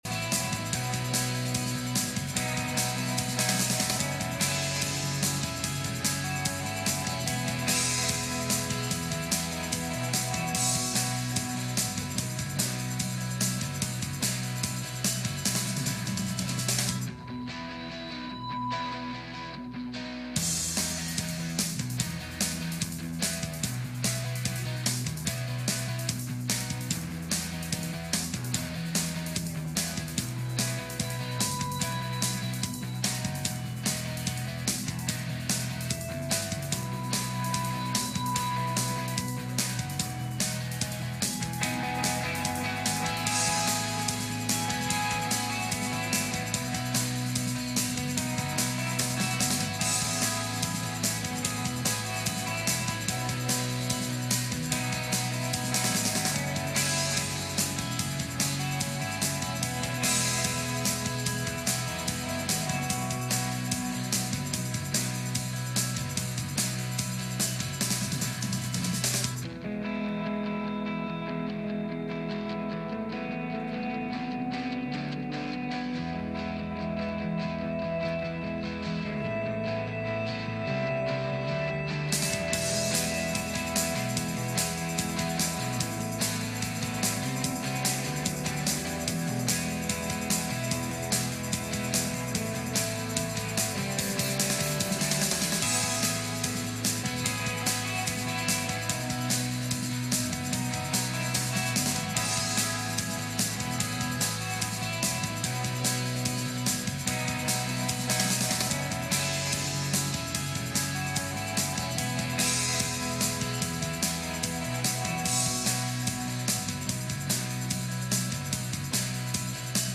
Psalm 107:23-32 Service Type: Midweek Meeting « Abigai Pt.2